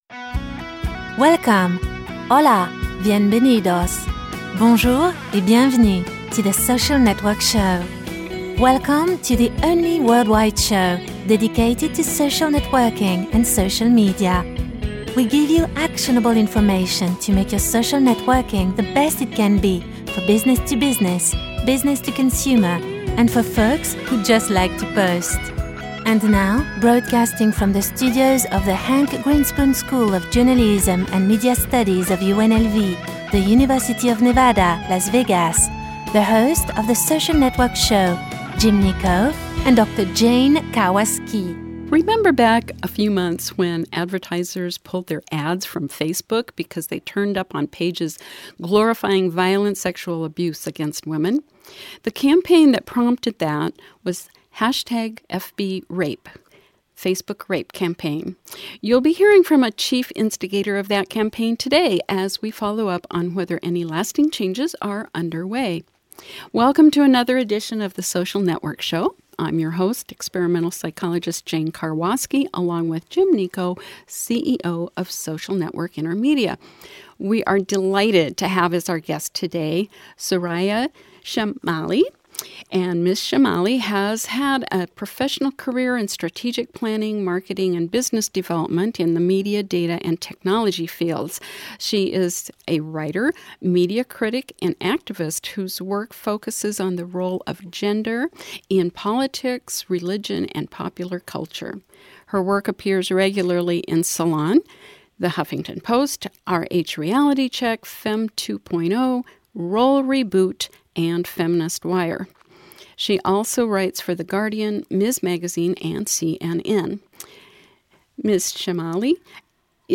The Social Network Show welcomes Soraya Chemaly to the May 6, 2014 episode.